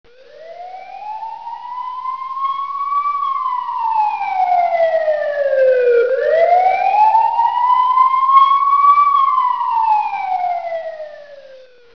" ~ ~ ~ GRANDPA'S GO CART ~ ~ ~ WANT TO HEAR GRANDPA'S HORN ?
SIREN1.wav